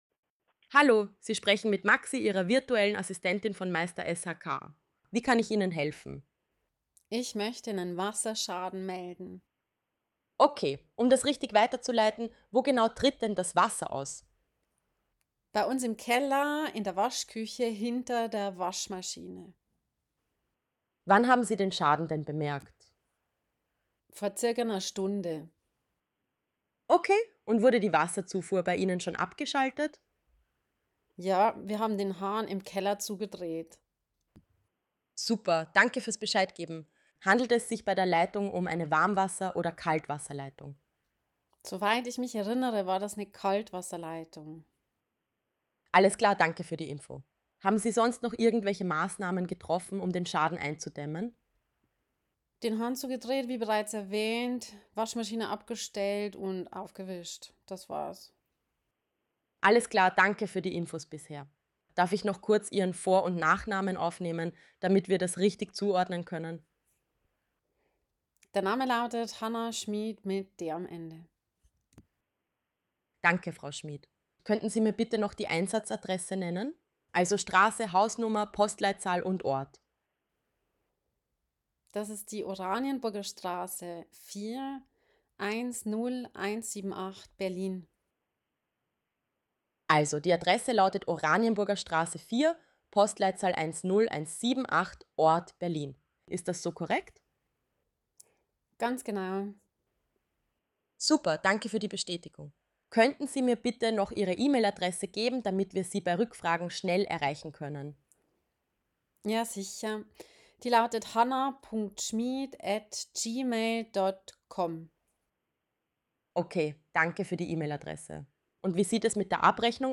Wasserschaden Beispielgespräch mit Frag Maxi
FragMaxi_SHK_Wasserschaden_mixdown.mp3